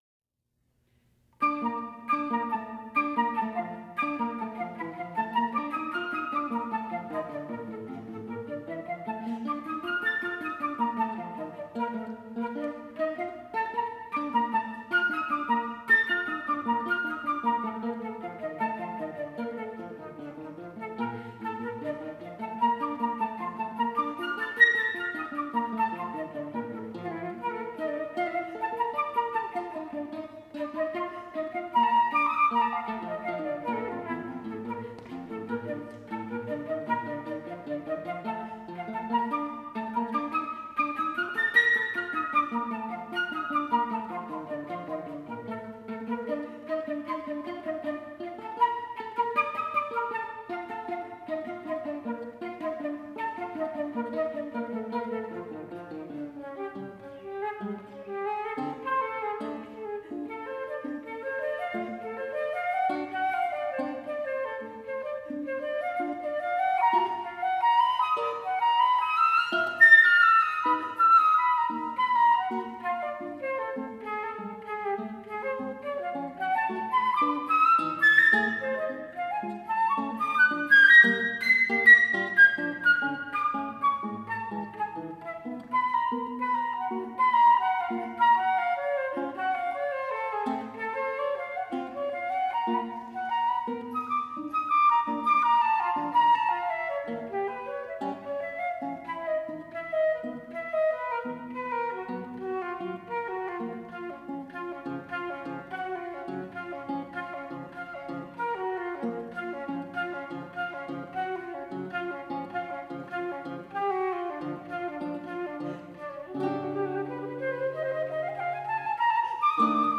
at New Music Festival 2012 at University of Louisville.